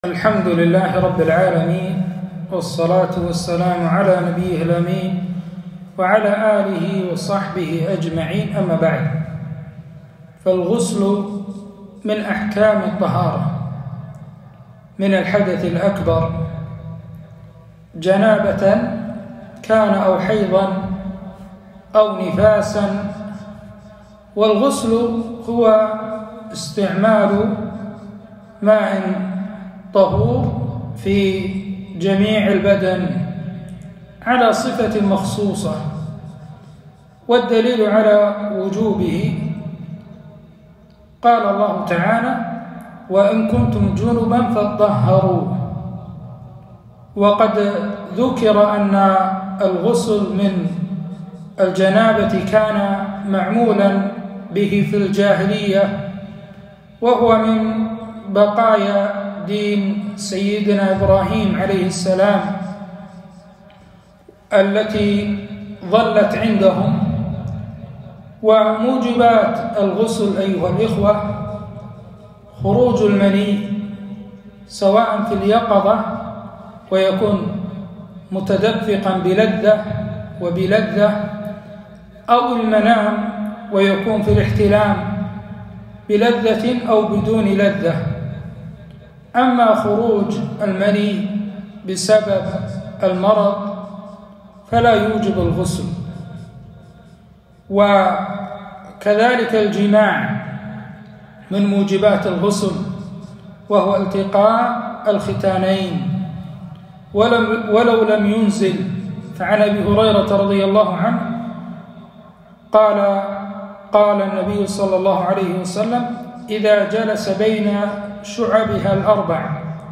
كلمة - كيفية الغسل وموجباته وأنواعه